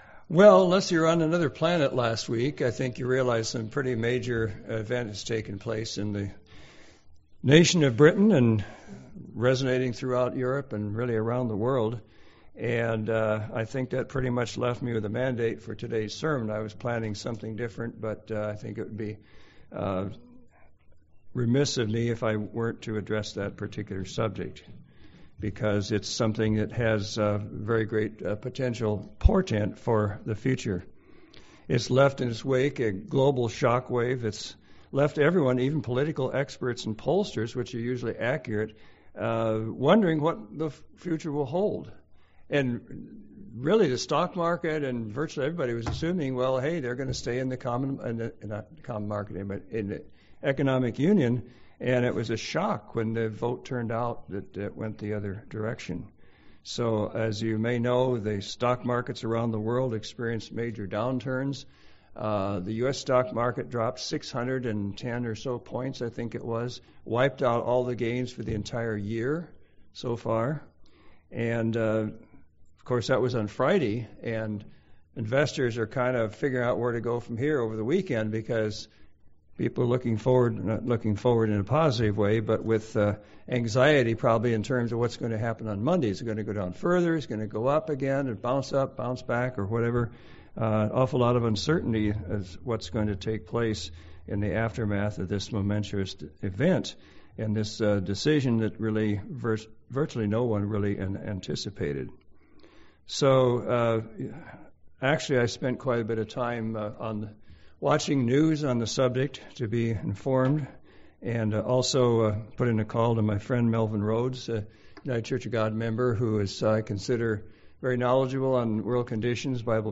Introduction – The monumental event that has taken place in Britain has taken center stage in world news and pretty much left me with as a mandate for today’s sermon topic.